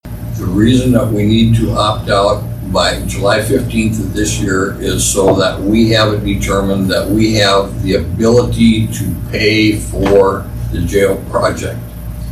As the July 15th opt out date approaches, the Walworth County Commissioners discussed some of their options for building a jail at their regular meeting on Tuesday, June 15, 2021.
Commissioner Rick Cain explained.